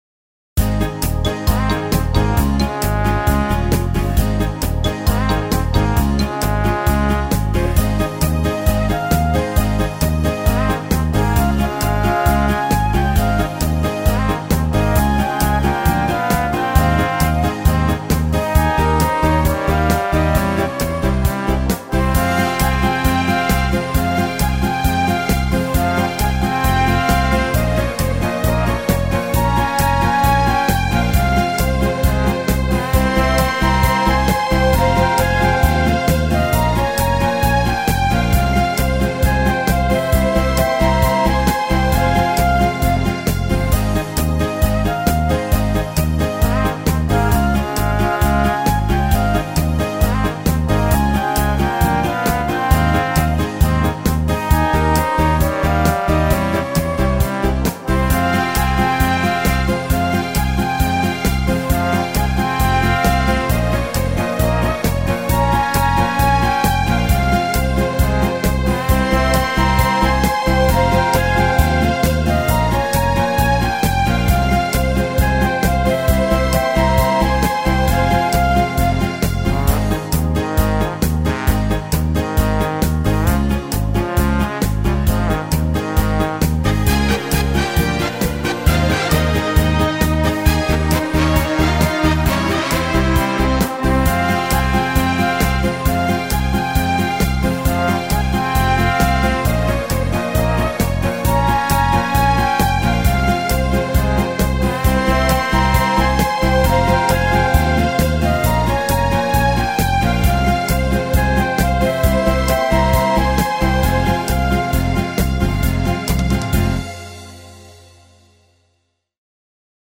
Физкультминутка (песня о маме - движения.).